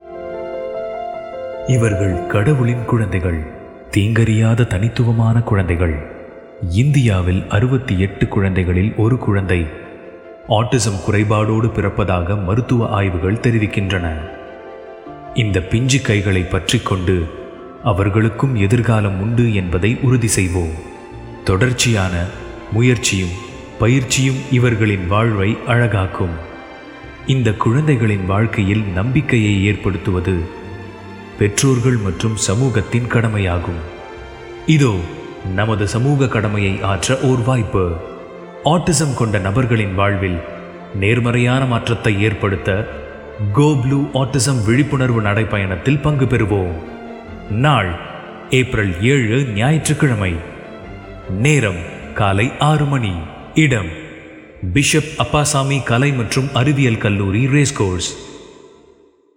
Autism Awareness Walkthon – Radio Commercial
AUTISM-AWARENESS-WALKATHON-PROCESS-VOX-WITH-MUSIC.wav